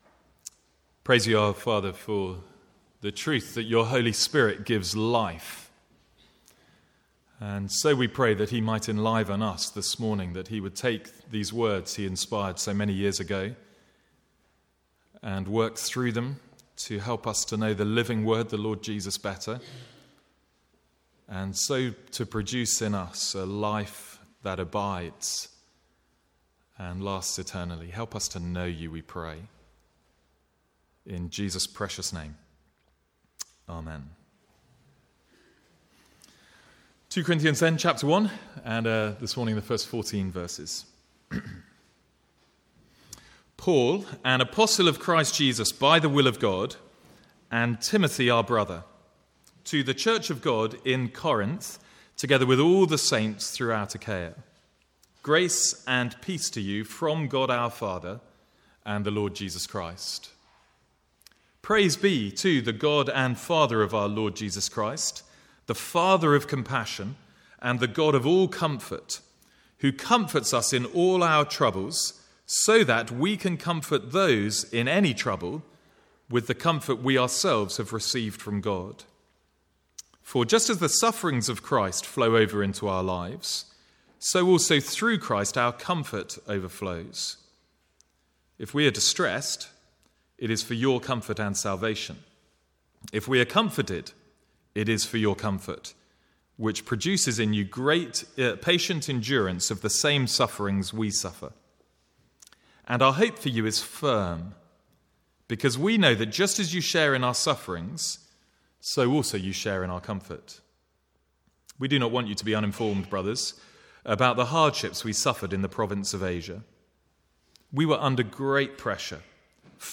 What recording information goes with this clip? From the Sunday morning series in 2 Corinthians.